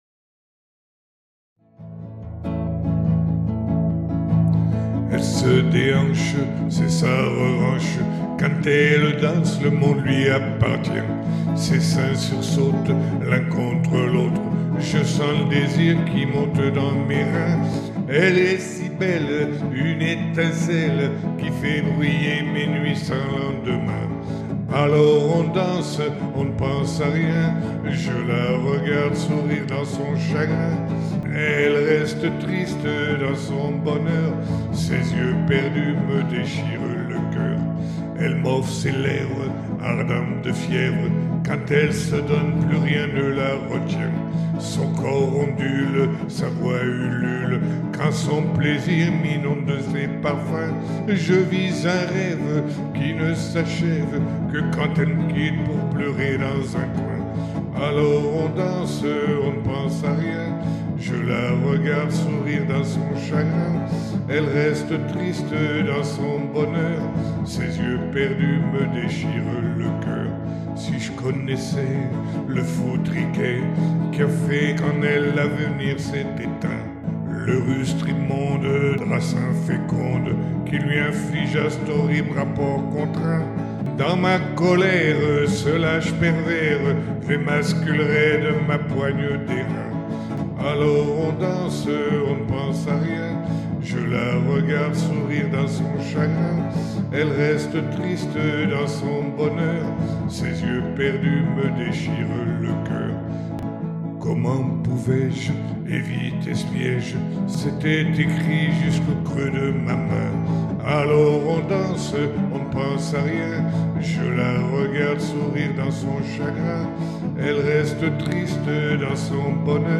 Glamour